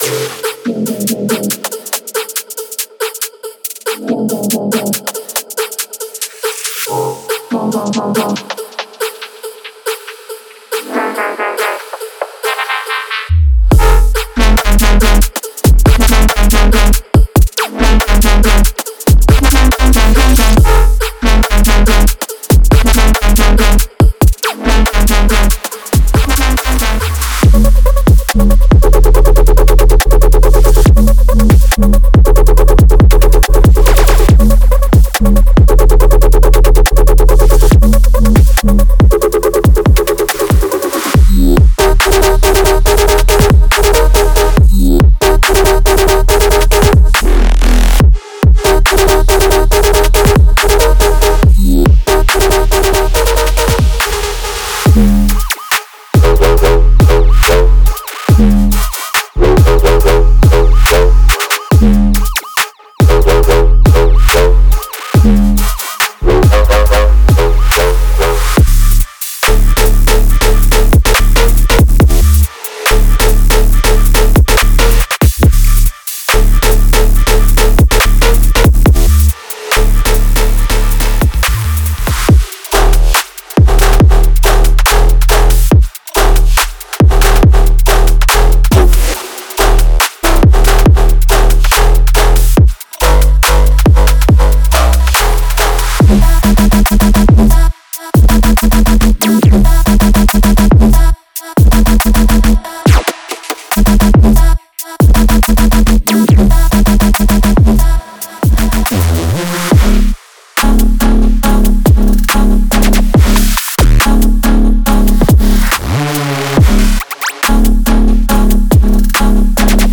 Genre:Bass Music
デモサウンドはコチラ↓